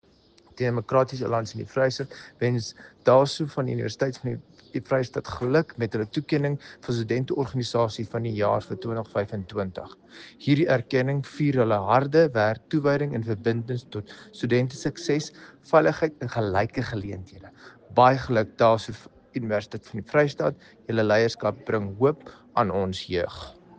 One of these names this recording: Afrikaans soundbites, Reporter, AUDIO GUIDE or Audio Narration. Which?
Afrikaans soundbites